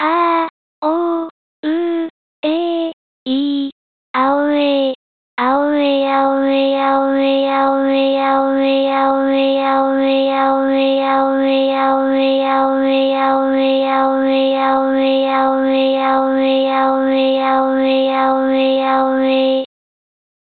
アオウエイ　　（音声読み上げソフト）
女声-2）
hz-aouei-fem-2.mp3